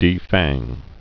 (dē-făng)